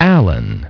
Транскрипция и произношение слова "alan" в британском и американском вариантах.